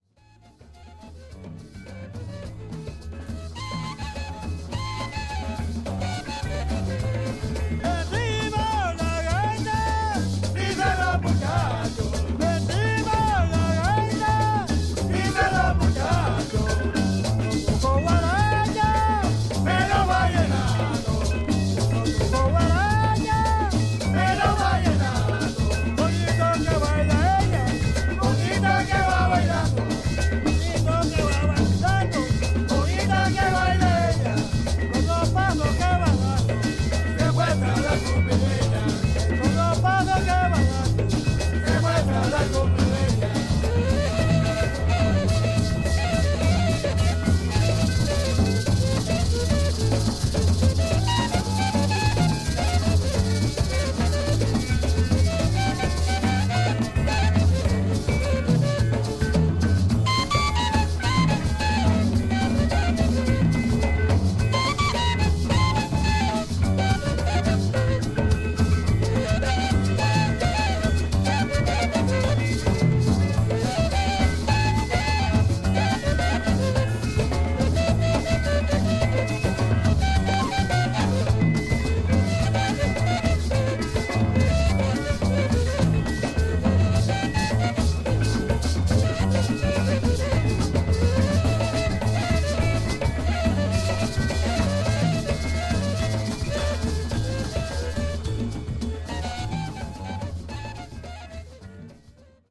Tags: Folklorico , Tropical , Colombia , Bogotá
gaita con band